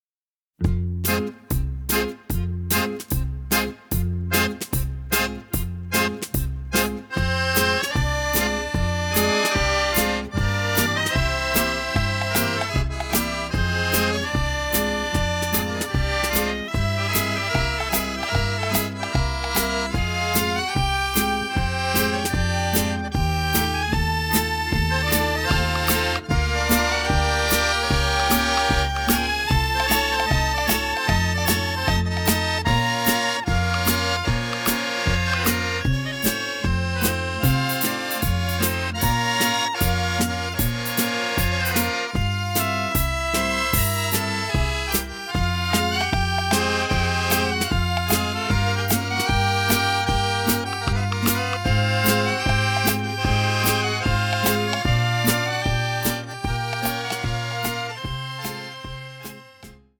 melancholic passages with atonal and dissonant moments